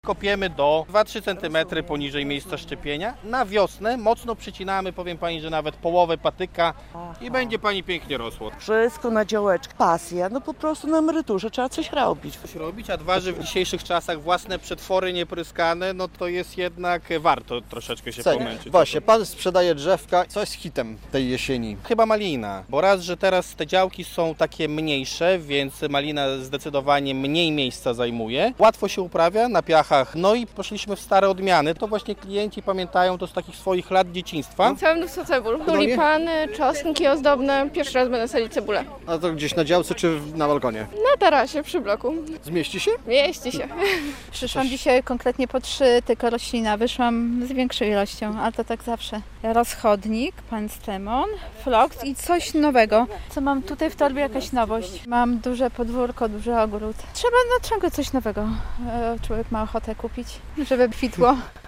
Jarmark Ogrodniczy "Kresowy Ogród" przed Teatrem Dramatycznym w Białymstoku - relacja